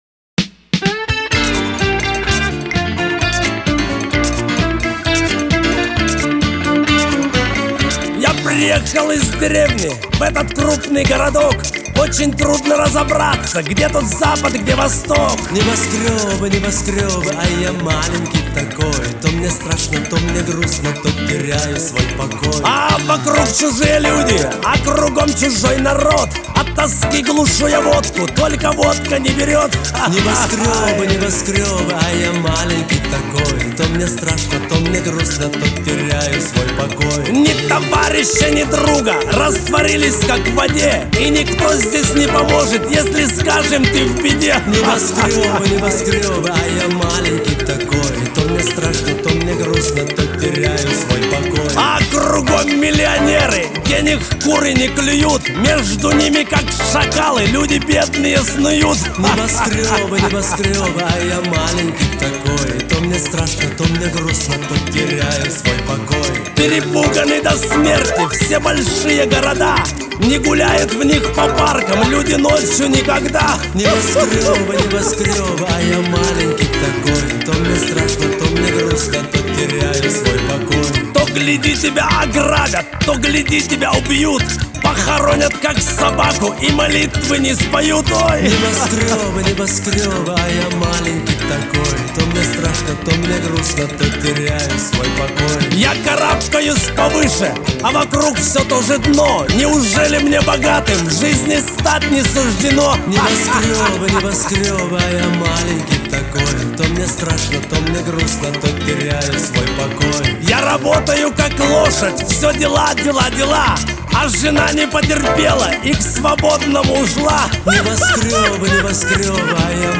Жанр: Chanson